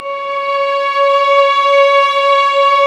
Index of /90_sSampleCDs/Roland LCDP13 String Sections/STR_Violins II/STR_Vls6 p%mf St